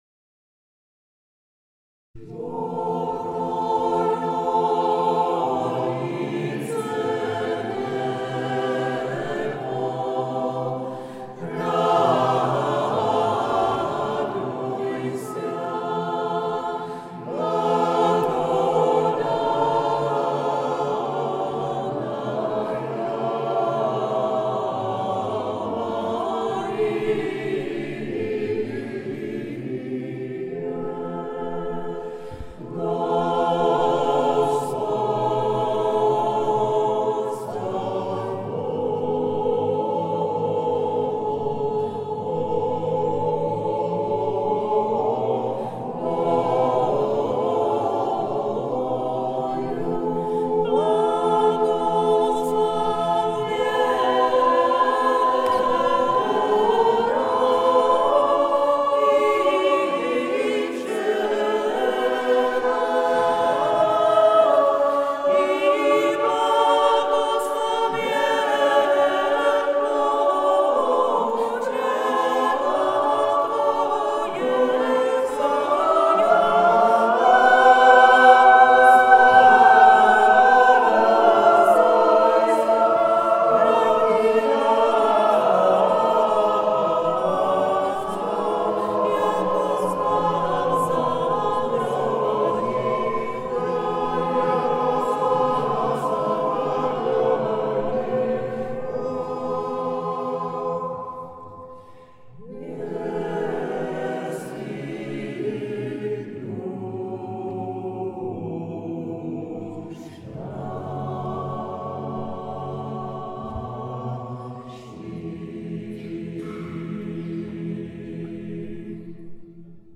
Zvuková ukázka z vystoupení v aldenském kostele Panny Marie